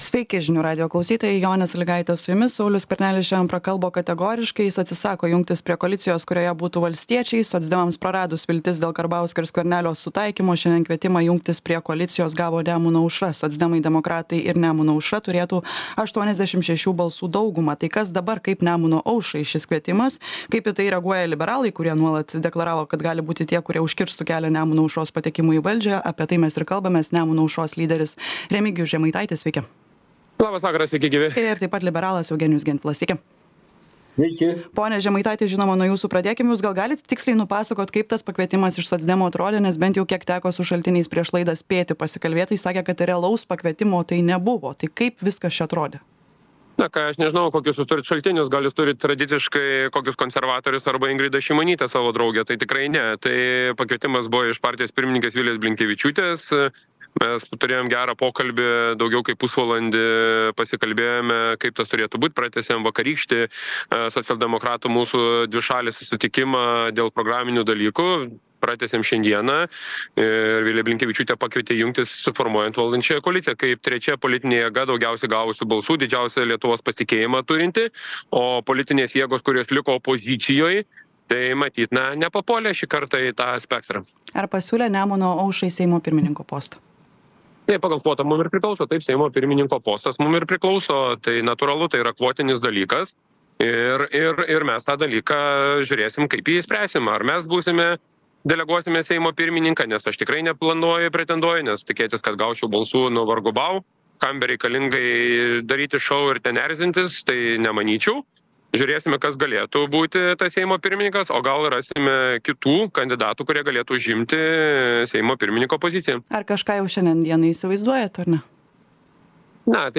Laidoje dalyvauja partijos „Nemuno aušra" lyderis Remigijus Žemaitaitis ir Liberalų sąjūdžio vicepirmininkas Eugenijus Gentvilas.